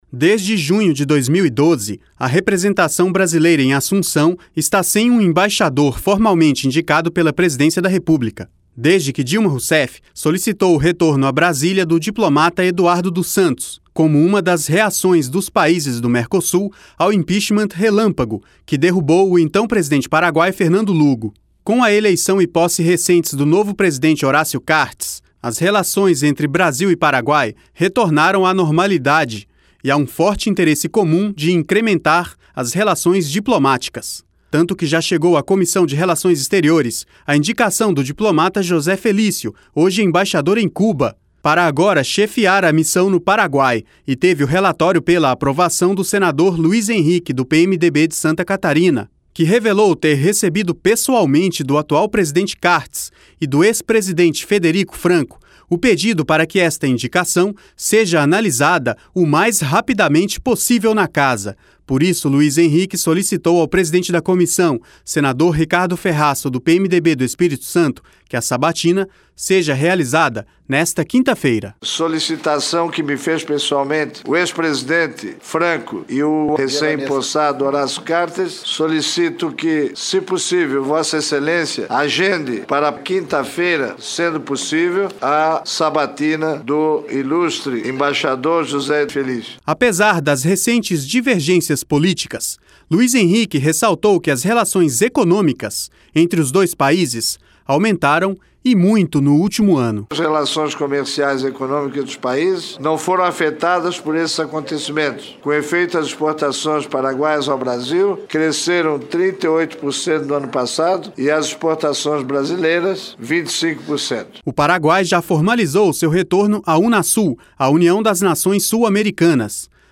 (REP): Apesar das recentes divergências políticas, Luiz Henrique ressaltou que as relações econômicas entre os dois países aumentaram e mui